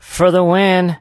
sandy_start_vo_04.ogg